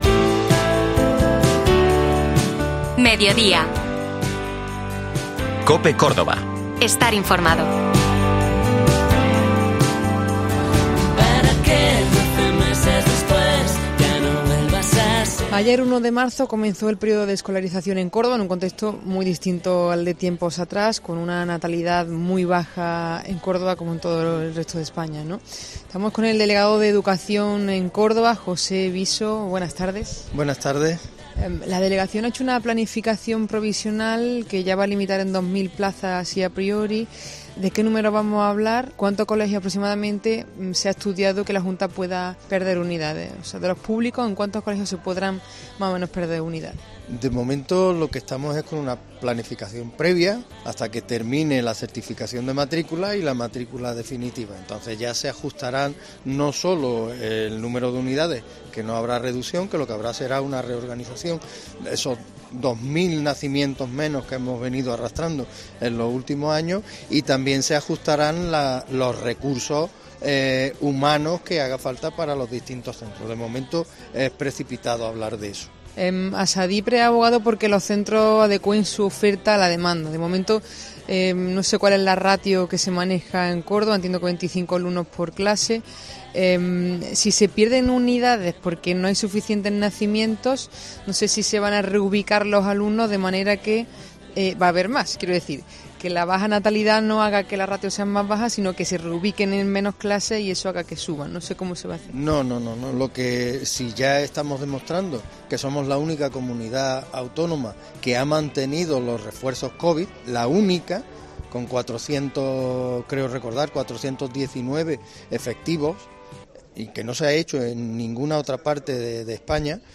Escucha la entrevista al delegado territorial de Educación, Francisco José Viso